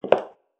inside-step-1.wav